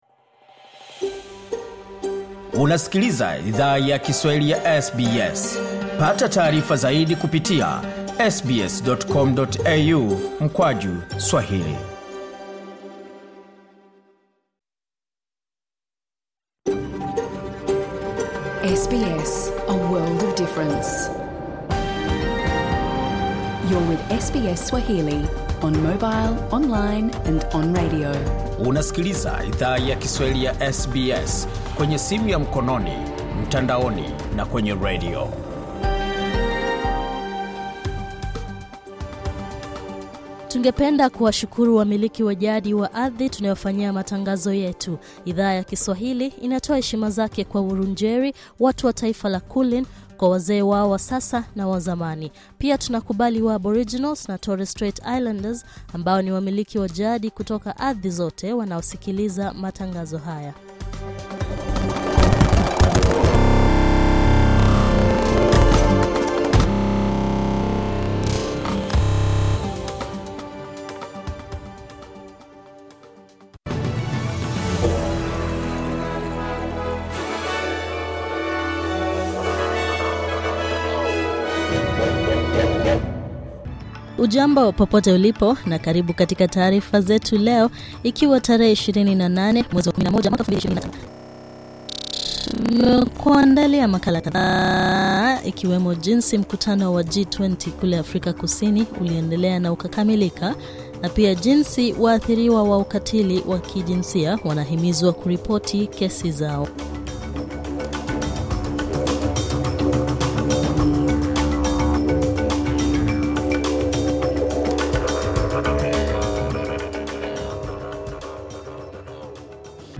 Taarifa ya habari: Moto Hongkong umeingia siku ya pili,mamia bado hawajapatikana